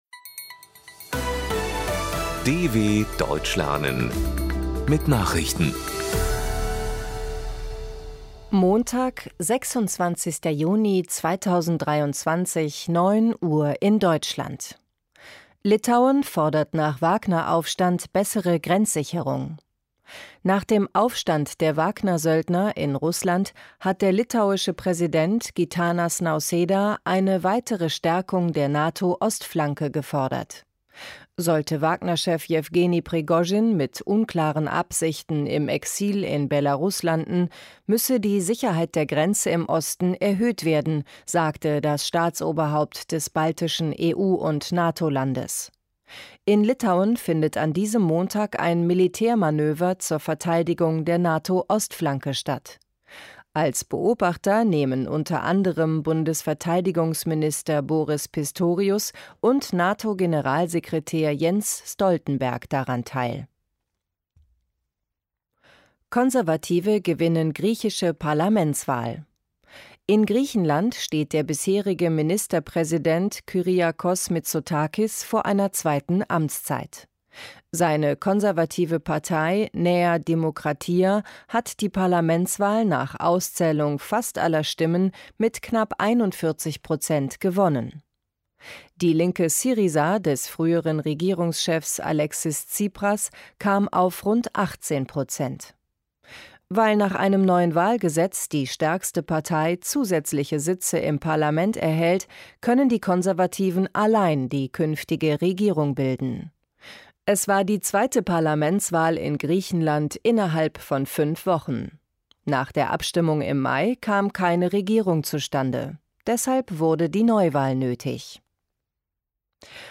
26.06.2023 – Langsam Gesprochene Nachrichten
Trainiere dein Hörverstehen mit den Nachrichten der Deutschen Welle von Montag – als Text und als verständlich gesprochene Audio-Datei.